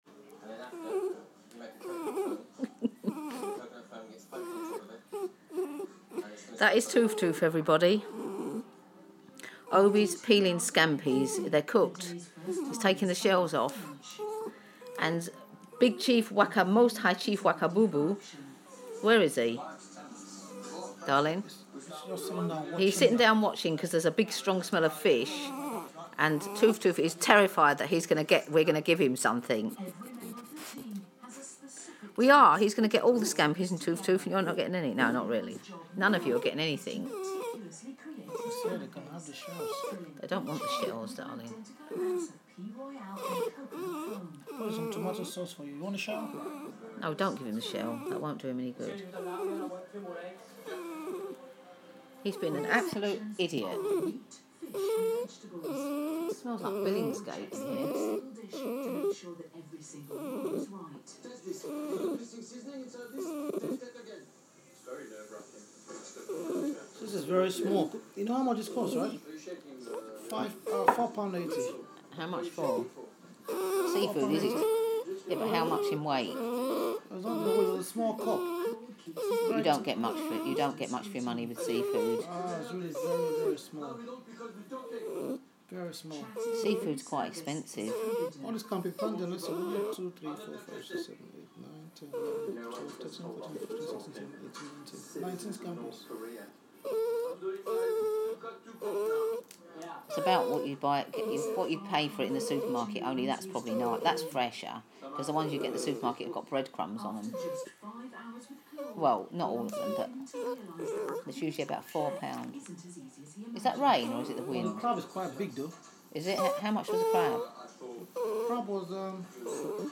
Toof Toof singing for his supper, feeding the cats and general chat (a bit of a soundscape)